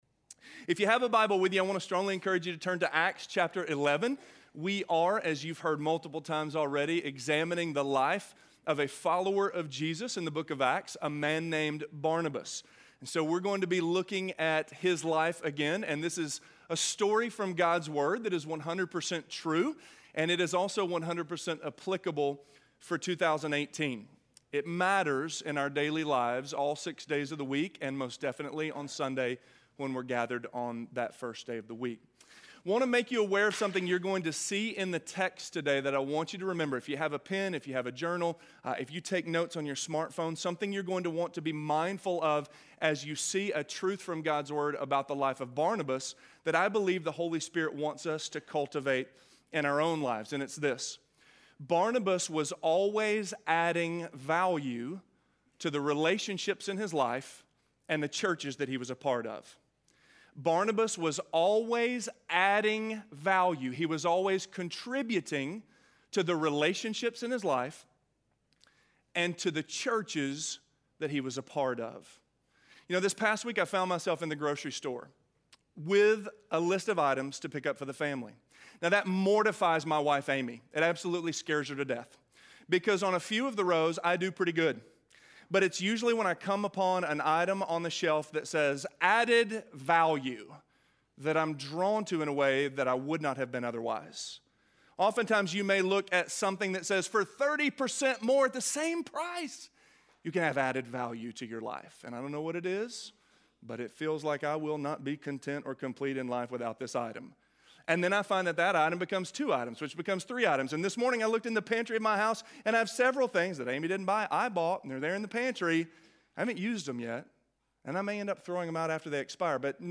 Just Like Barnabas: Affirming - Sermon - Avenue South